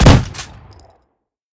pump-shot-8.ogg